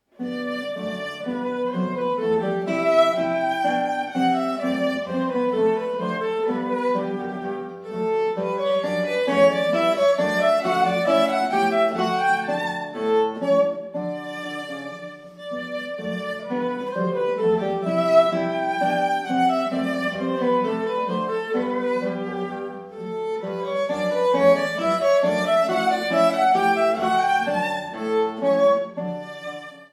Duo
Viola
Konzertgitarre